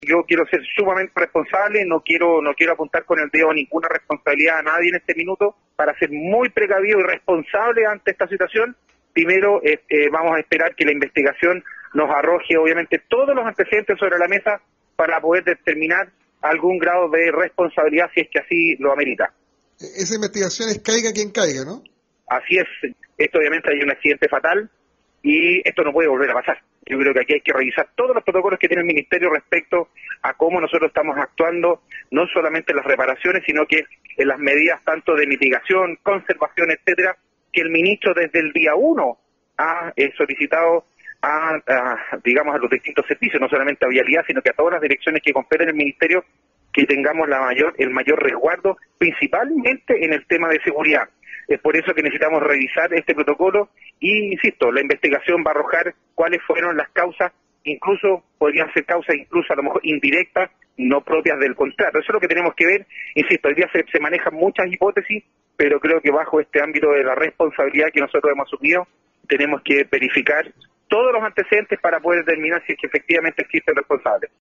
El Ministerio de Obras Públicas en la región de Los Lagos va a investigar las causas del colapso del Puente Cancura sobre el río Rahue. Asi lo indicó el seremi de la cartera, James Fry, quien en conversación con Radio SAGO sostuvo que había que estudiar todos los antecedentes, incluído el por qué se tomó la decisión de reparar el puente en sus tres bases El antecesor de Fry en el MOP, había indicado a los vecinos, y a la comunidad a través de los medios, que el Puente no estaba en peligro, a pesar de las denuncias de los dirigentes del sector de que la extracción de áridos estaba exponiendo las bases de la infraestructura.